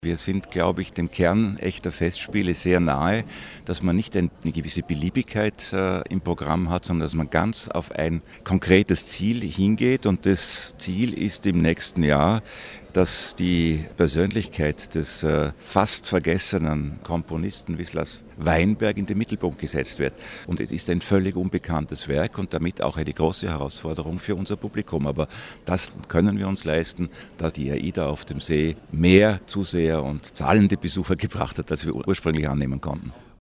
Audio PK Gesamtprogramm News